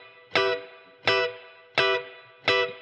DD_TeleChop_85-Emin.wav